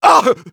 Engineer audio responses/de
Engineer_painsharp02_de.wav